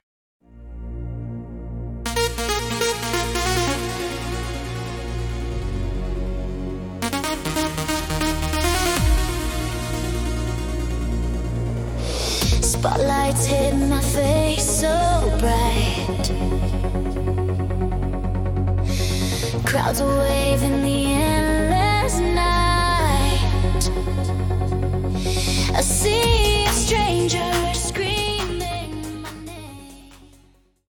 Style : Trance, Eurodance